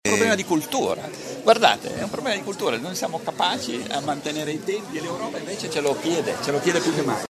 A Bologna per un convegno in Regione su formazione professionale e ricerca industriale il Ministro ha portato anche buone notizie.